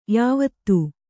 ♪ yāvattū